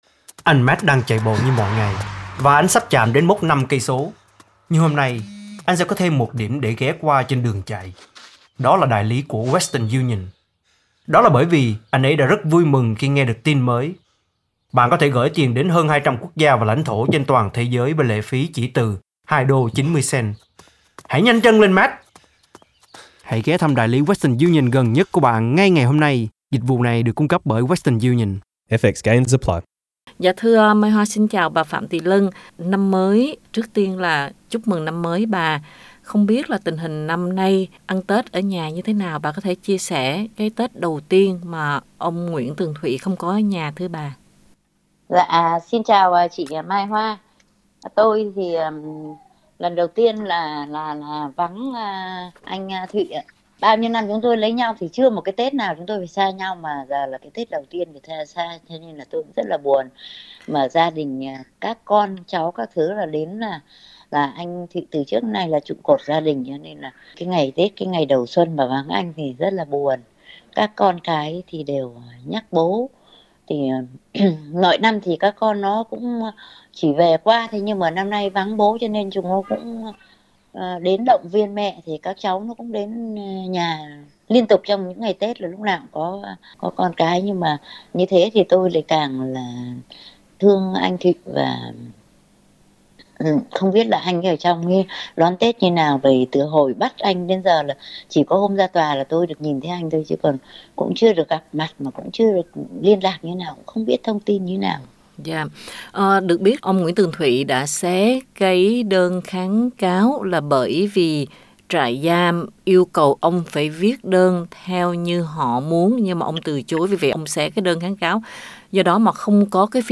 Tết năm nay ông bị tù tội, bà đọc lại bài thơ ông làm cho bà năm ngoái trong tiếng cười nhớ ông.